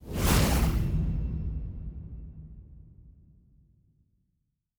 pgs/Assets/Audio/Sci-Fi Sounds/Interface/Complex Interface 5.wav at master
Complex Interface 5.wav